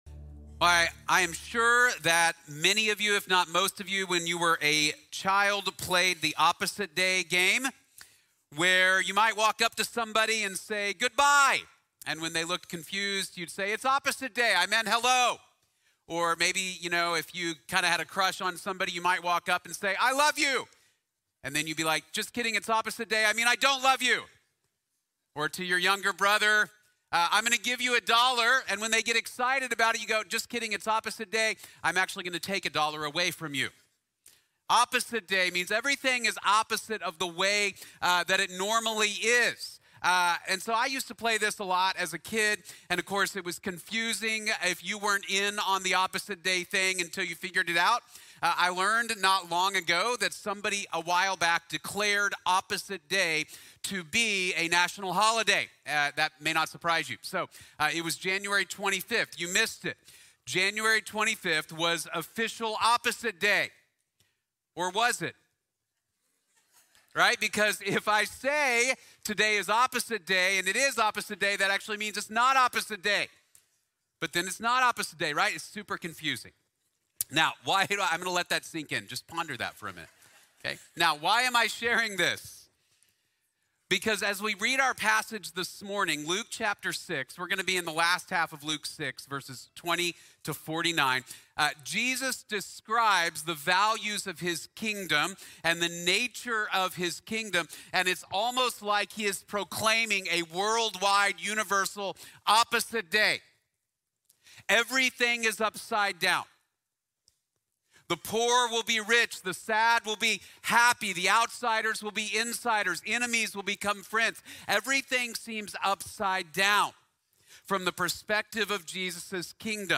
Opposite Day | Sermon | Grace Bible Church